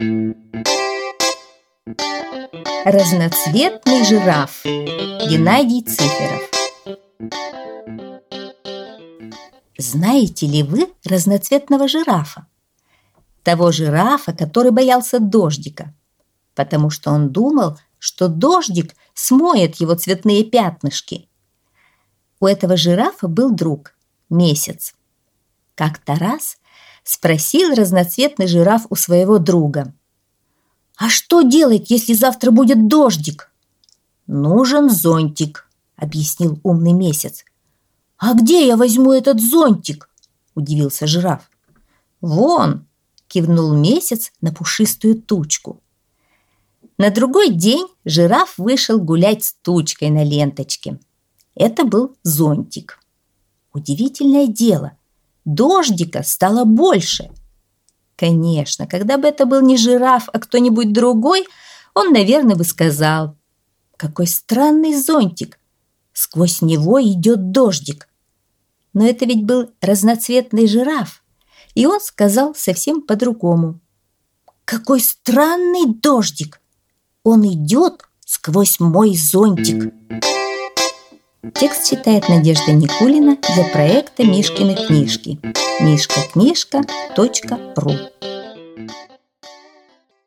Аудиосказка «Разноцветный жираф»